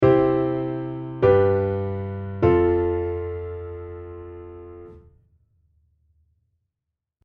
この３つ目の和音には、色んな選択肢があります。